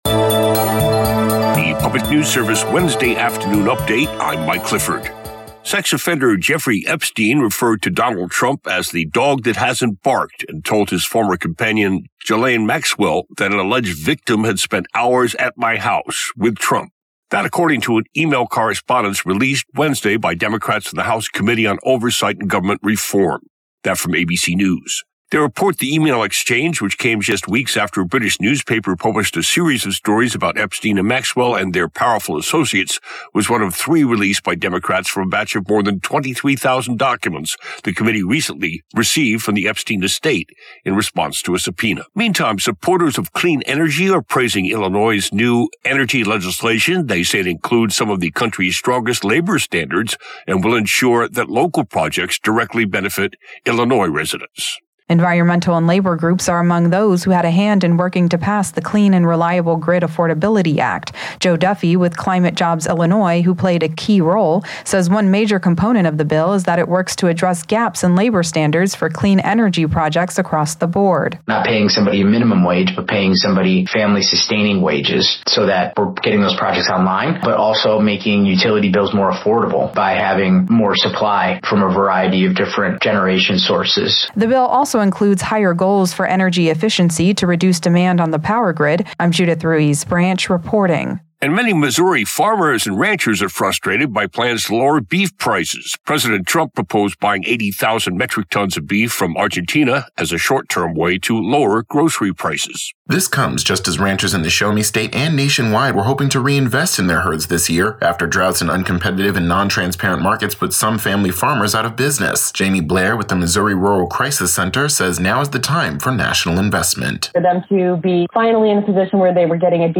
Afternoon news update for Wednesday, November 12, 2025